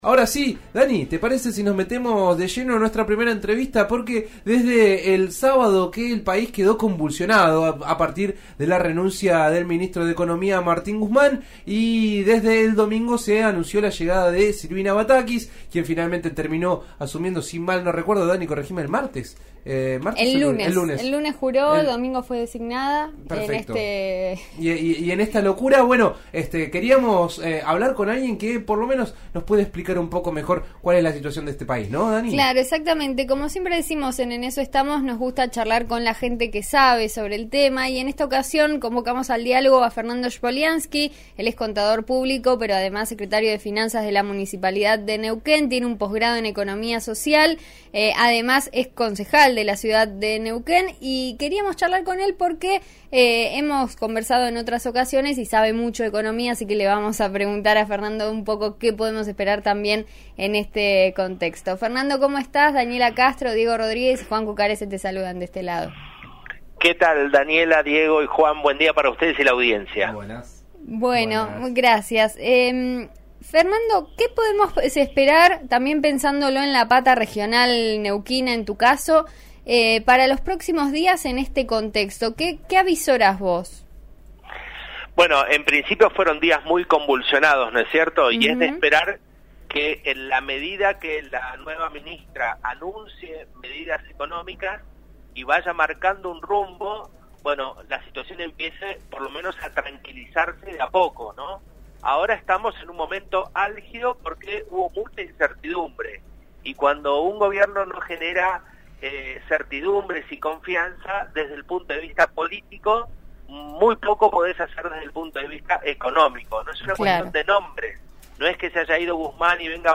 En eso estamos de RN Radio dialogó con Fernando Schpoliansky, Secretario de Finanzas de la Municipalidad de Neuquén y Contador Público, sobre la actualidad económica del país luego de la asunción de Silvina Batakis como ministra de Economía.